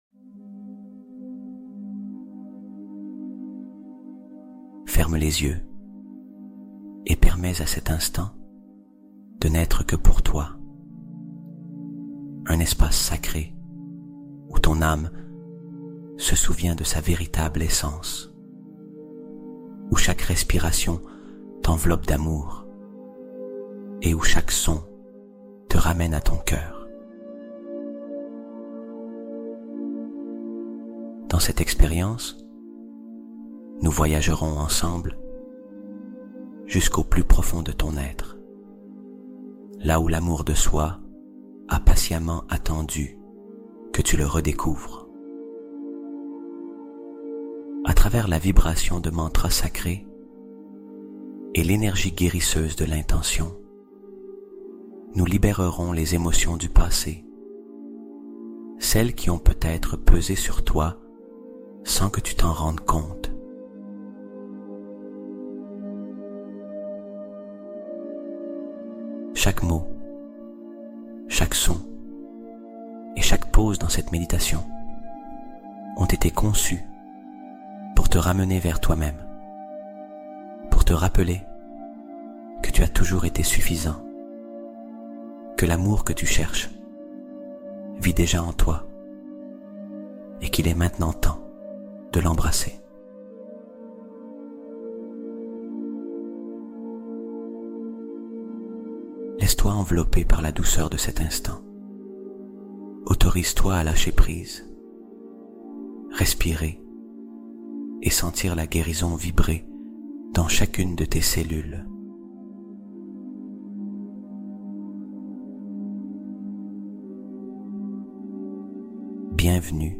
Amour de Soi : Mantras de guérison pour restaurer son estime personnelle